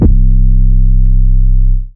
Dro 808 3.wav